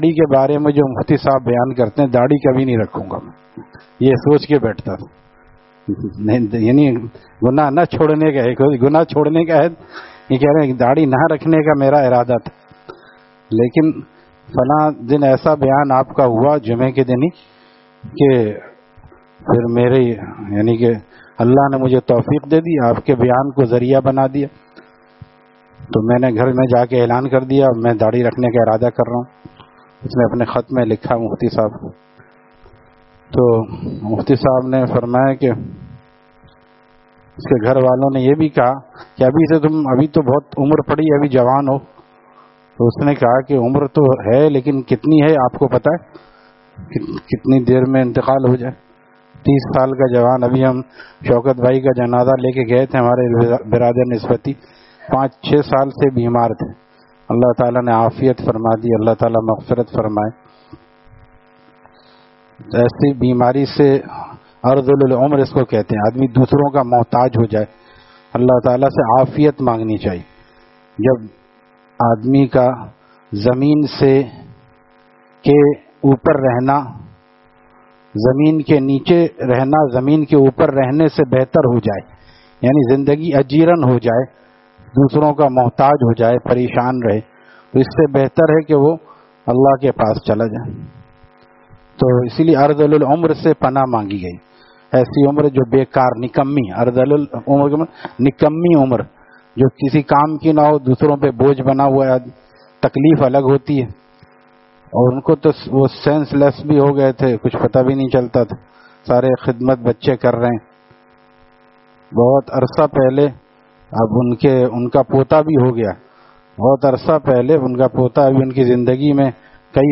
Saturday Markazi Bayan at Jama Masjid Gulzar e Muhammadi, Khanqah Gulzar e Akhter, Sec 4D, Surjani Town